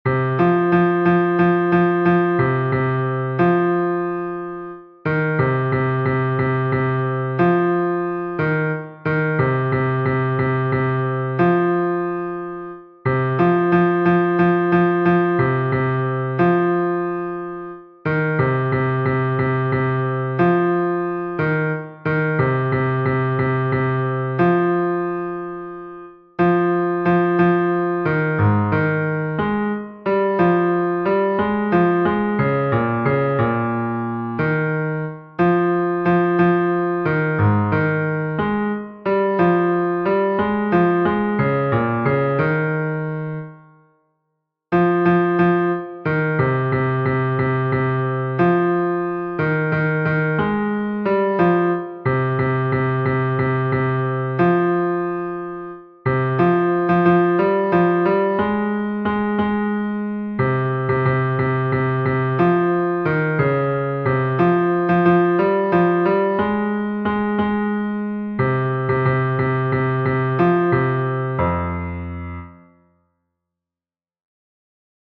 Fichier son basse
Nerea-izango-zen-Laboa-basse-V3-1.mp3